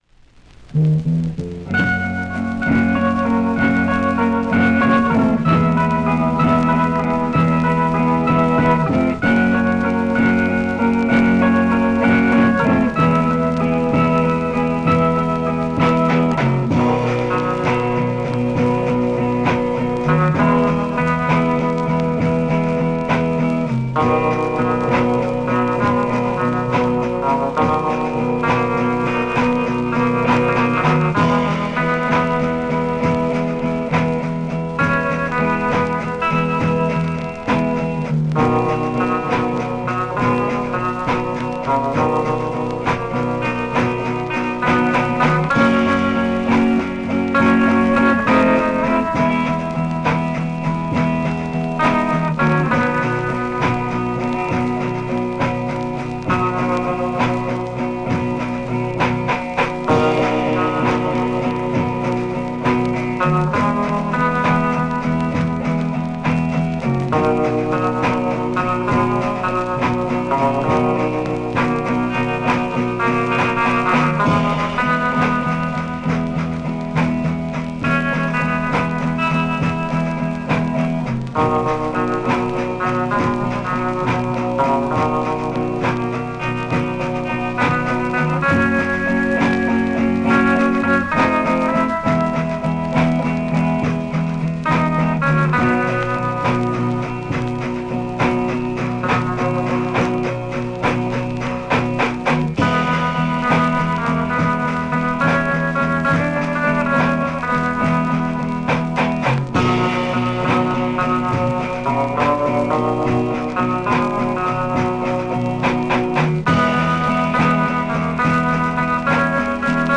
(unreleased acetate)